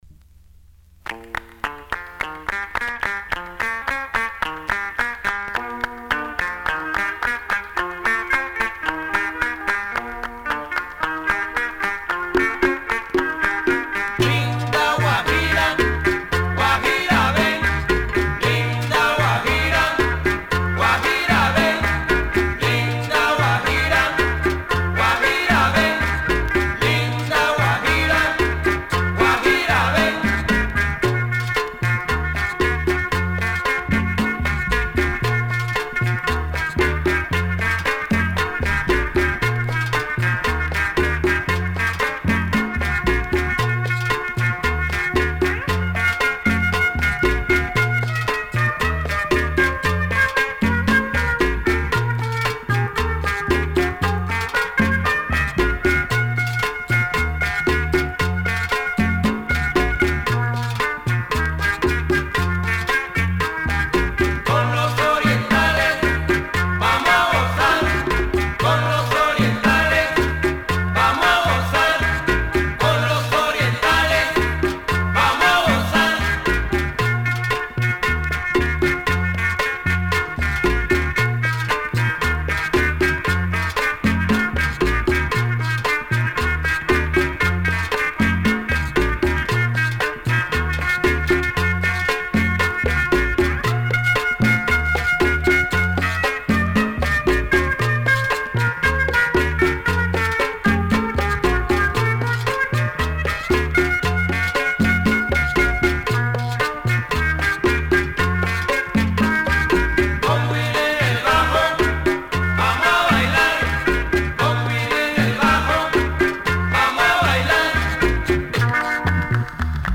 Super deep chicha with a touch of psychedelic rhythms.